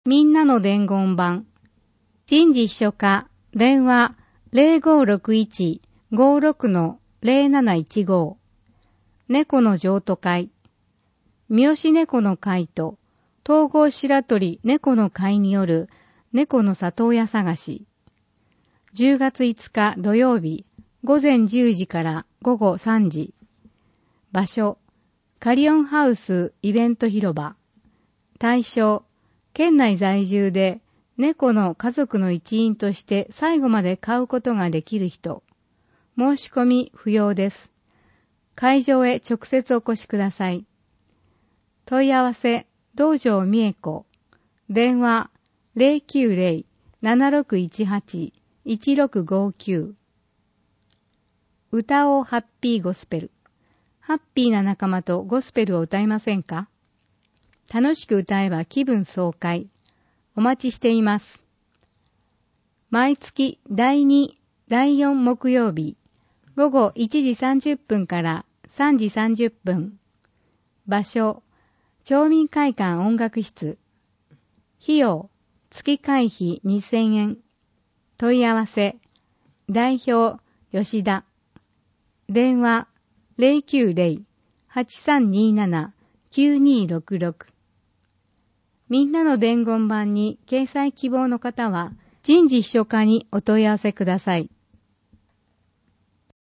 広報とうごう音訳版（2019年10月号）